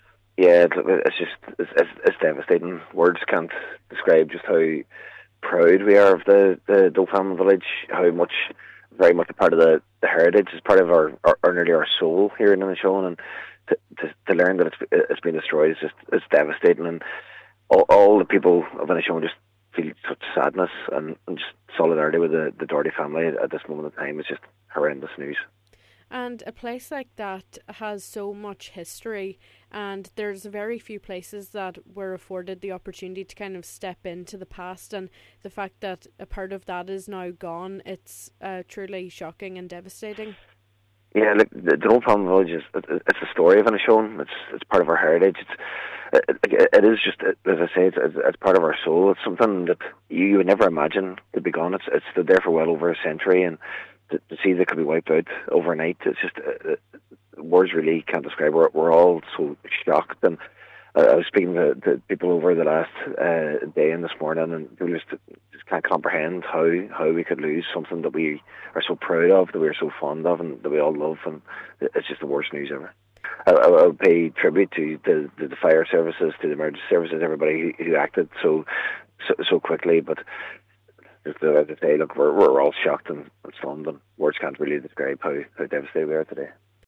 Cathaoirleach of the Inishowen Municipal District Jack Murray, says the community is devastated…